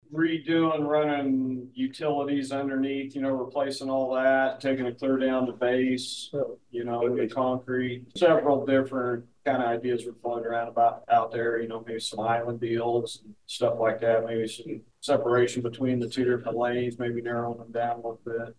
Riley County Commissioners held their Monday meeting at the Ogden Community Center, as part of a quarterly effort by the commission to take their meetings to constituents outside of Manhattan.
Ogden Mayor Robert Pence, Jr. provided commissioners a first update on the upcoming Riley Avenue corridor construction project at Monday’s meeting, which is estimated to be around $10 million, with around $2.5 million set aside from matching local grant funds.